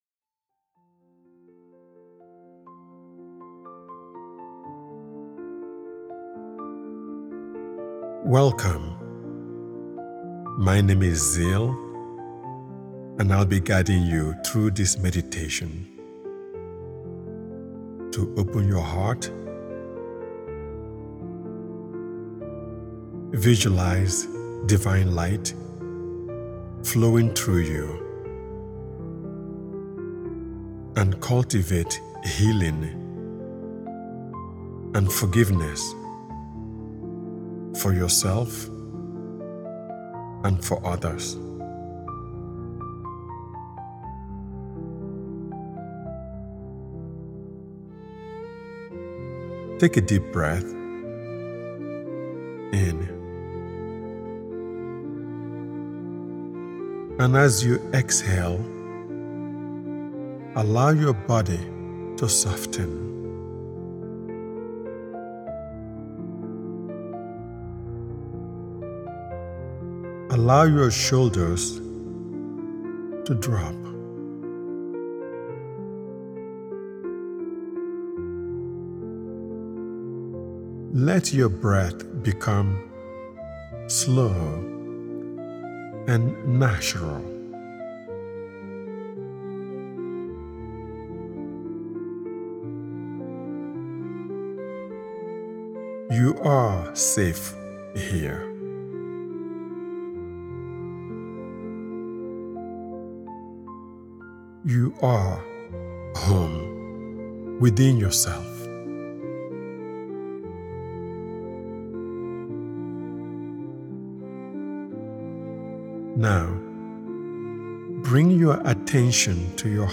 A Return To Love Meditation